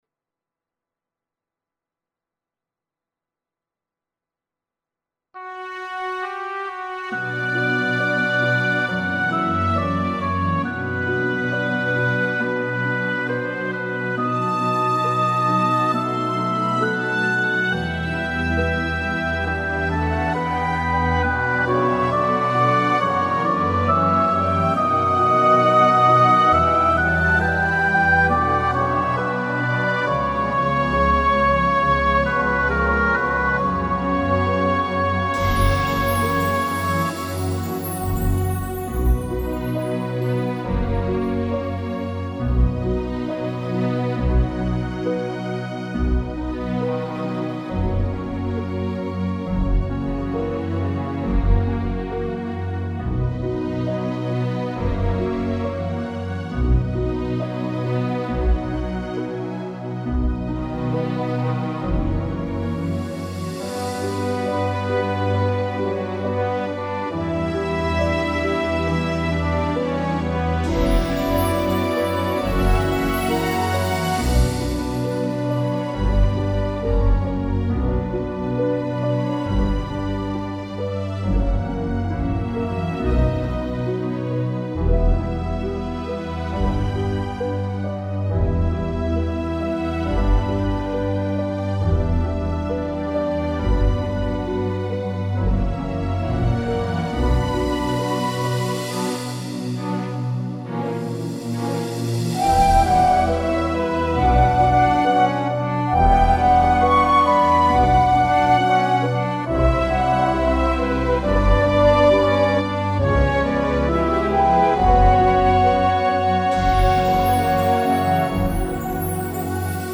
•   Beat  02.
Bm T68